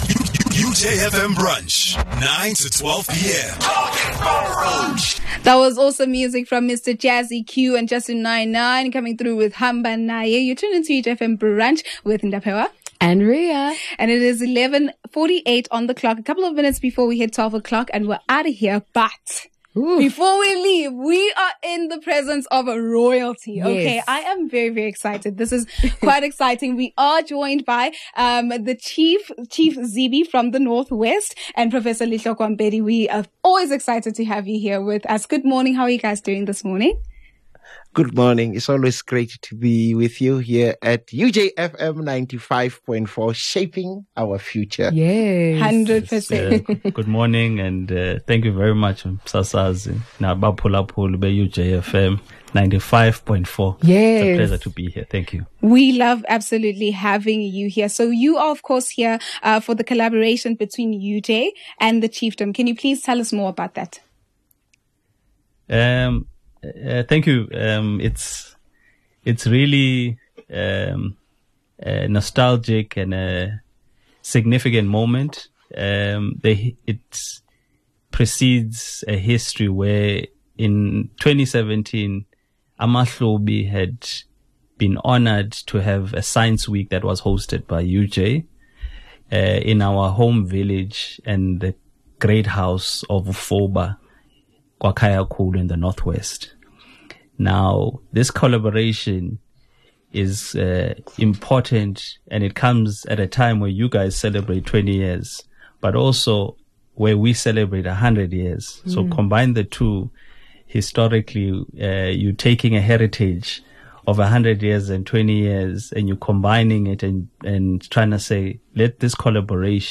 19 Mar A Conversation with Kgosi Lutho N. Zibi of Amahlubi & Vice-Chancellor and Principal, Professor Letlhokwa George Mpedi